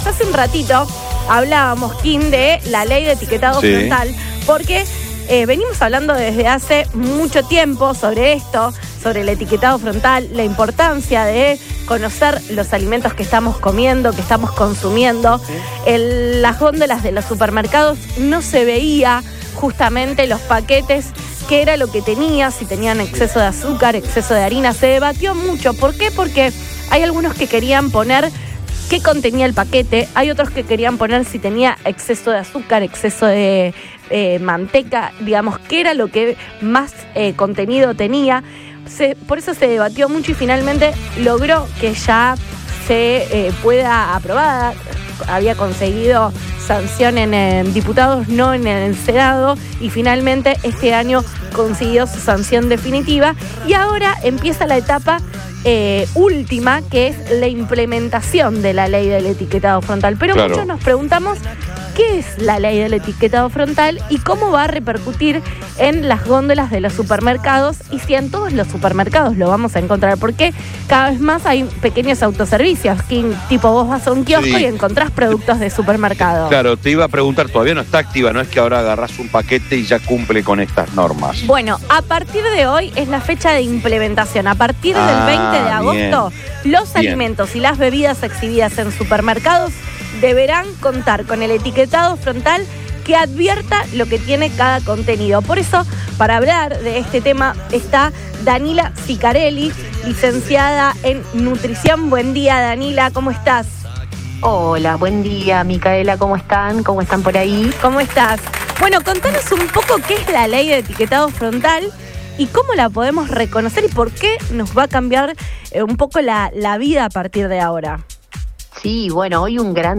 nutricionista.mp3